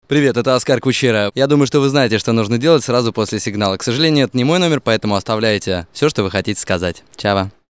** Приветствующие сообщения для автоответчика **